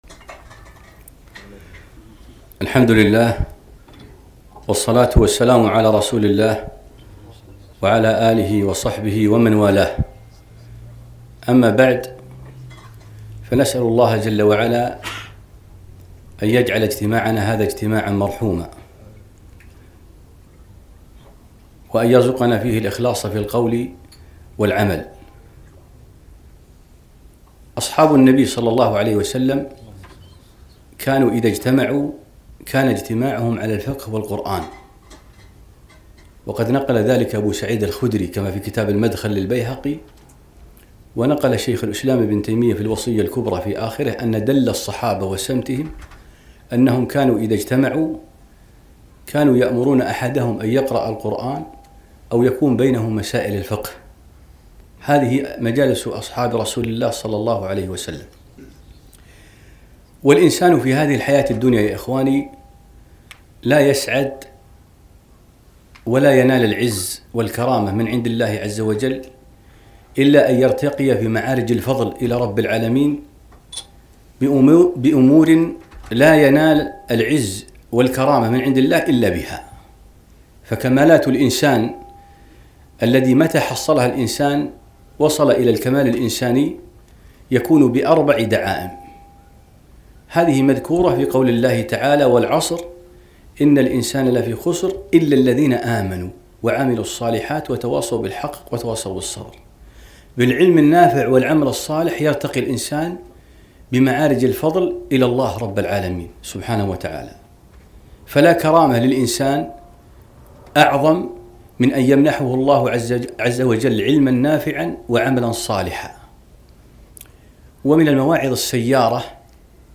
محاضرة نافعة - أهمية العلم والعمل